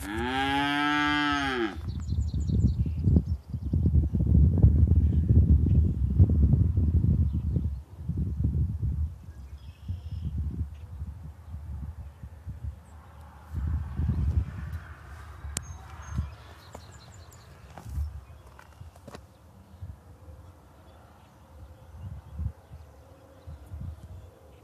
Dairy Beef Calves
Meet Noah, A Dairy Beef Calf!
Hear my sound.